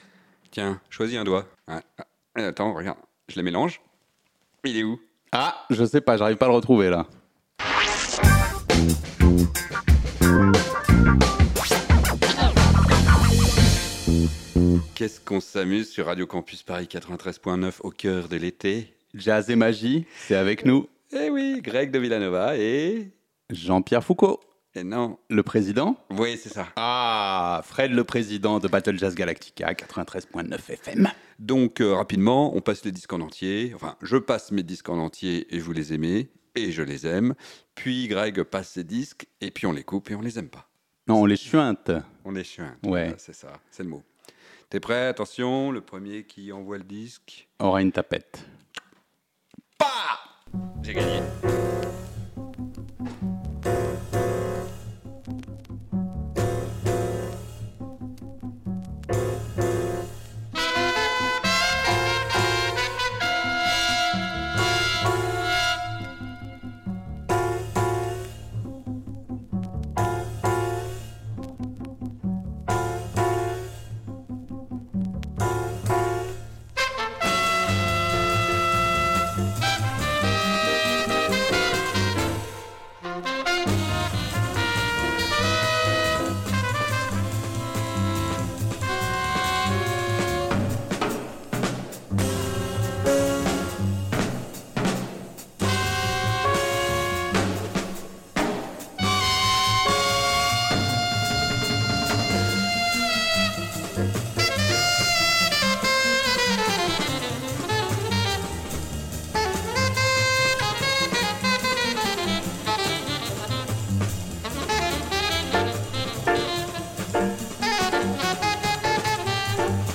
Classique & jazz